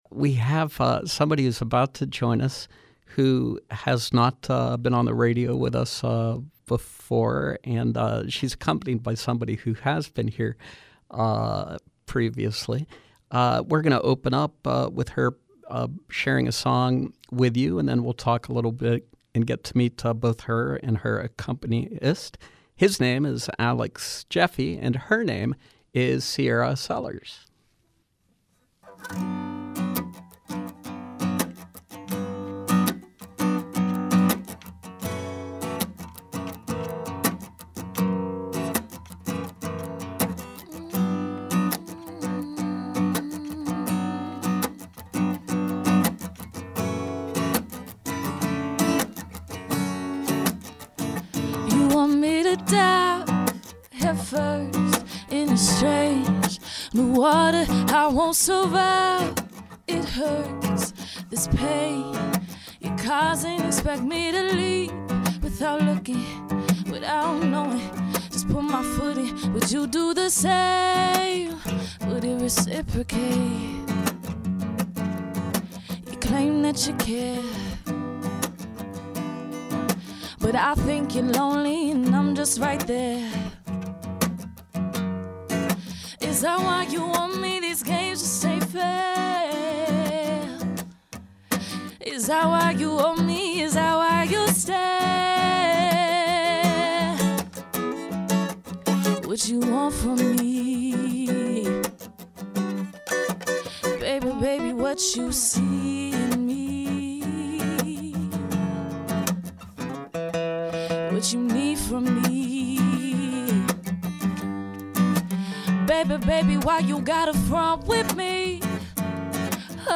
Local R&B soul singer